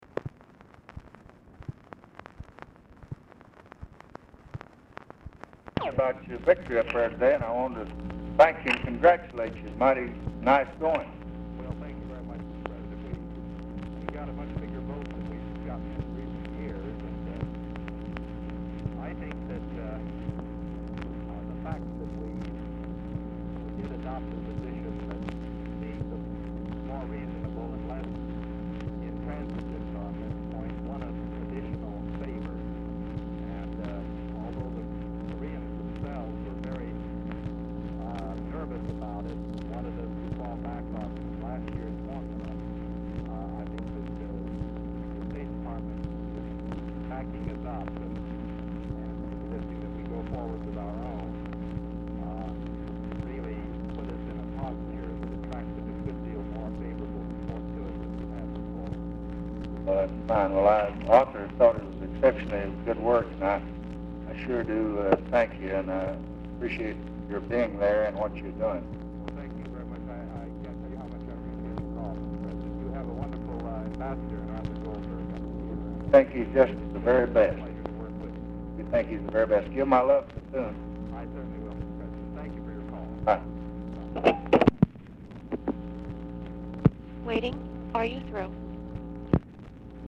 Telephone conversation # 11135, sound recording, LBJ and FRANK CHURCH, 12/13/1966, 6:53PM
RECORDING STARTS AFTER CONVERSATION HAS BEGUN; POOR SOUND QUALITY; CHURCH IS ALMOST INAUDIBLE
Dictation belt
Oval Office or unknown location